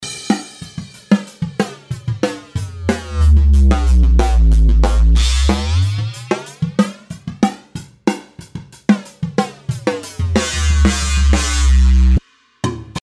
マウスで右下〜左上に動かした動作をオートメーションに記録したものを再生してみました。
今回コントロールしたパラメーターはFREQとDYNAMICSの2つ。
今回はドラムの音をコントロールしていますが、本来ならシンセの音に対してかけた方が効果的かもしれないですね。